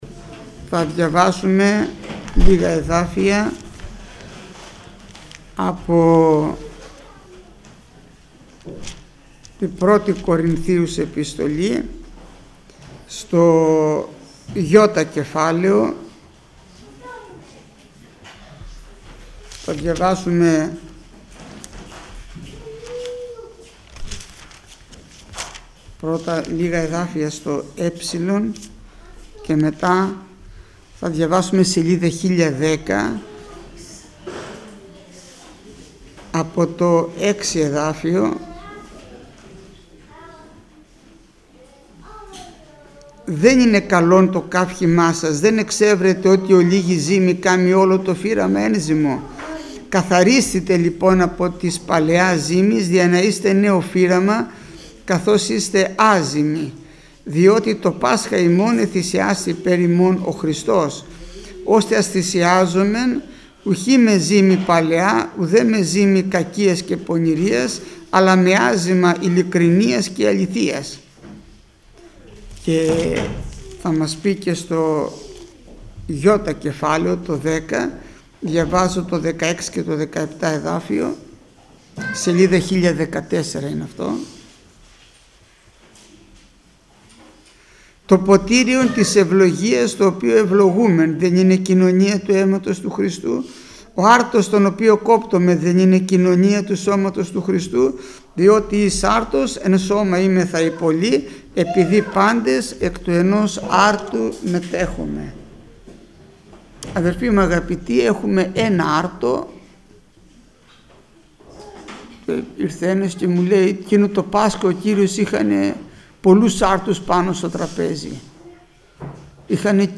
Μήνυμα πριν τη θεία κοινωνία
ΜΗΝΥΜΑΤΑ ΠΡΙΝ ΤΗ ΘΕΙΑ ΚΟΙΝΩΝΙΑ